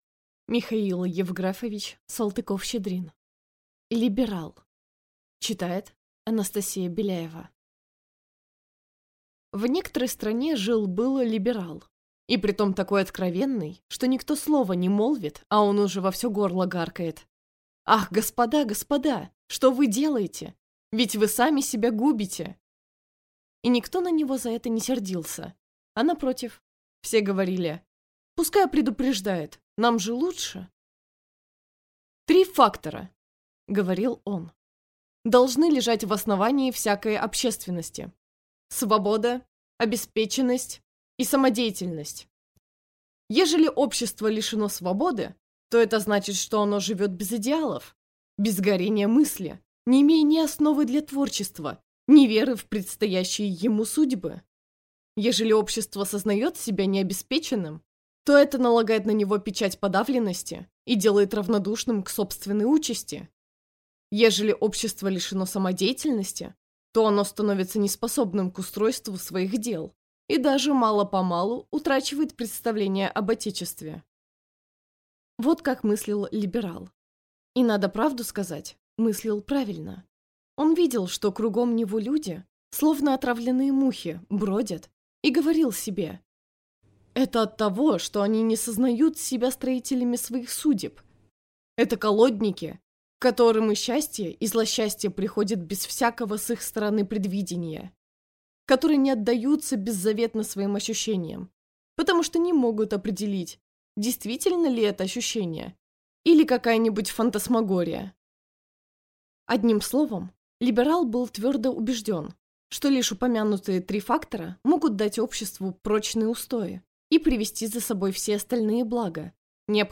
Аудиокнига Либерал | Библиотека аудиокниг